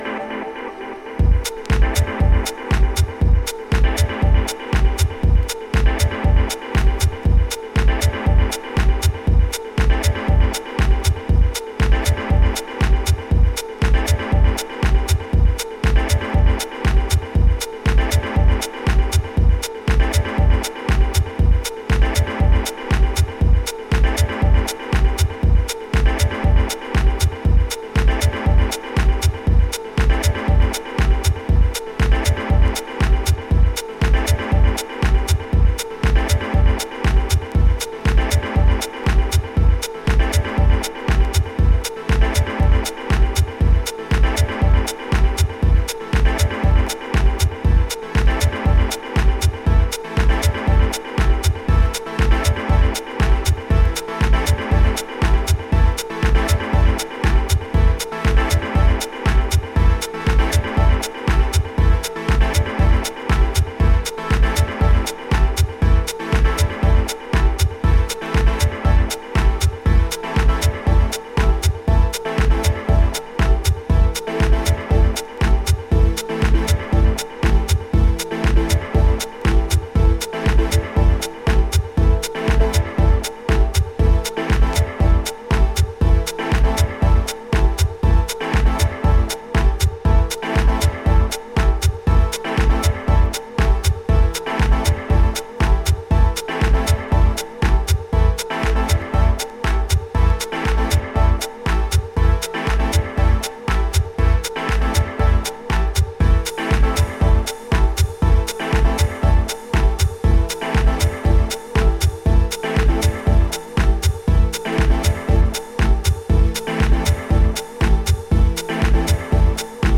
Super nice clean Detroit rooted house tracks!